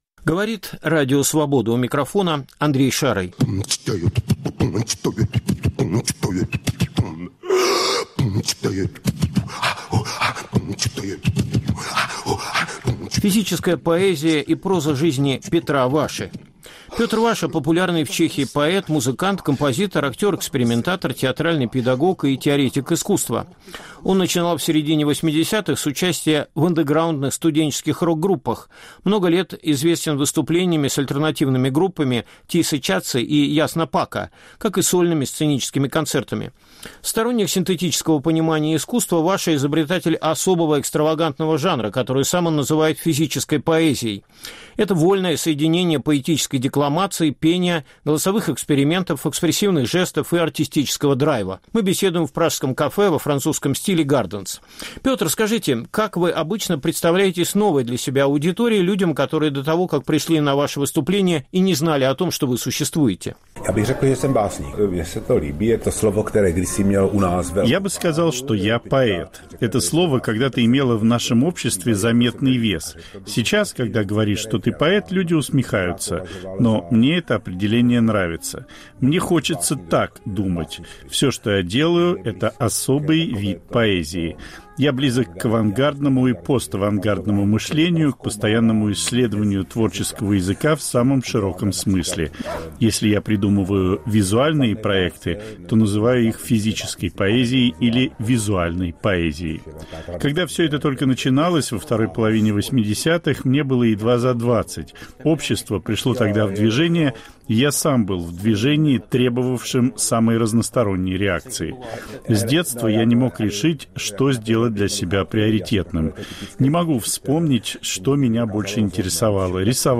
Беседа с чешским художником о понимании творчества и свободы